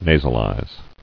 [na·sal·ize]